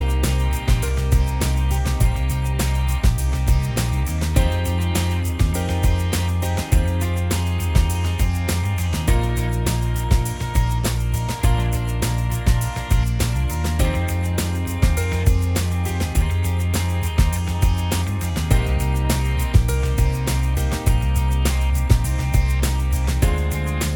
Minus All Guitars Except Acoustic Pop (2000s) 4:47 Buy £1.50